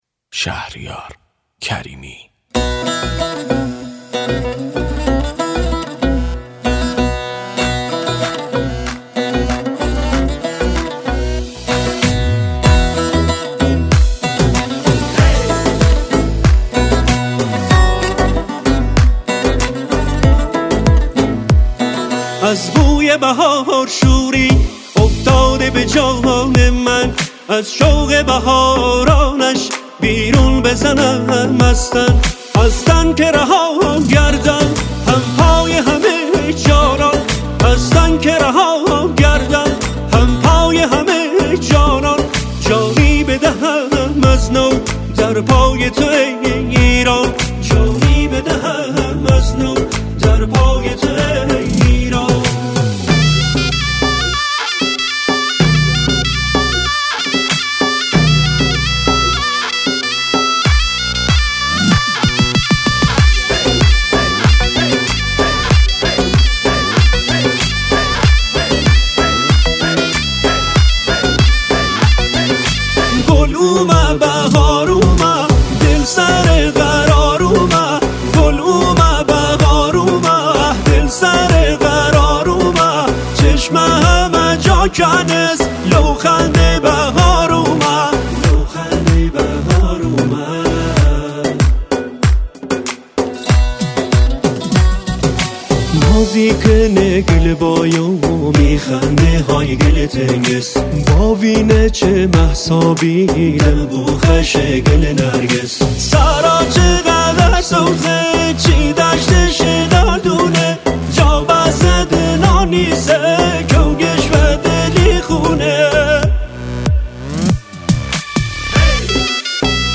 آهنگ لری کلاسیک
اهنگ لری بویر احمدی ممسنی